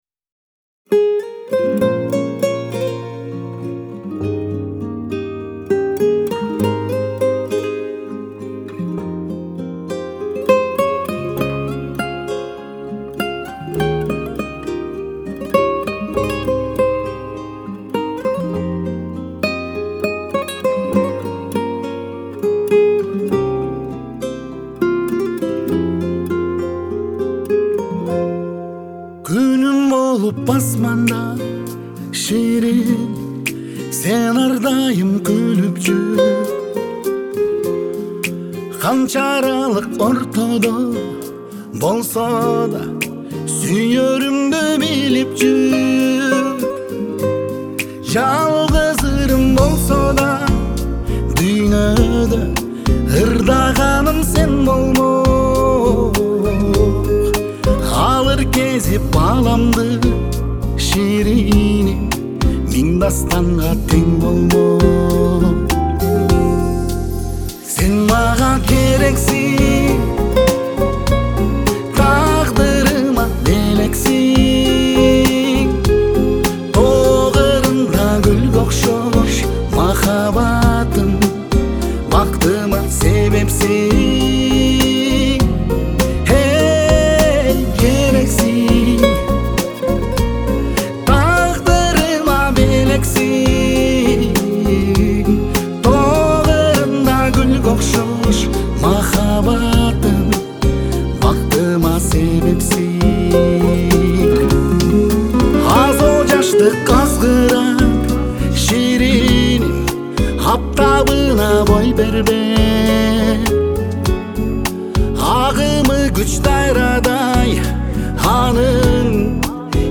Трек размещён в разделе Русские песни / Киргизская музыка.